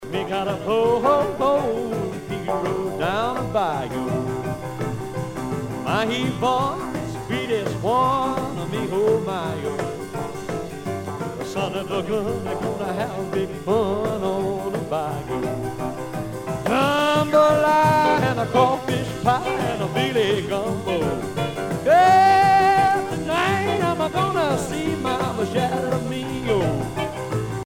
danse : rock